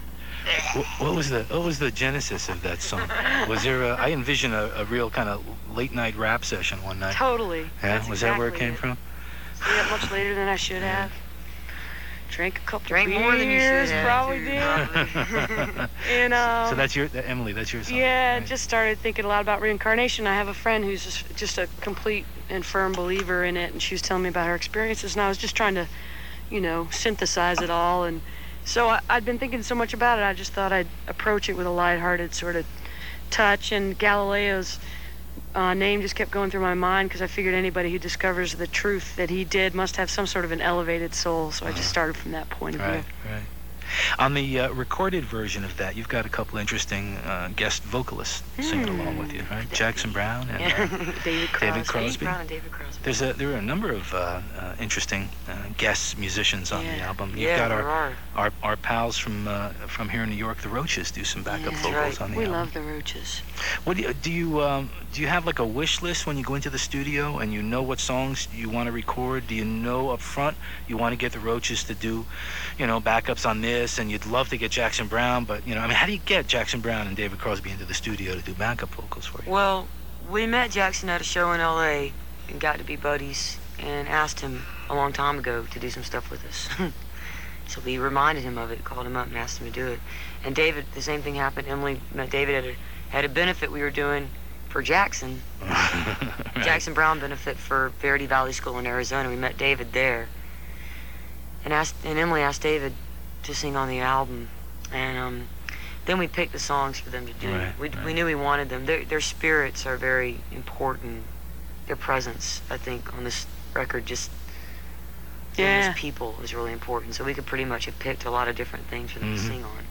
05. interview (4:20)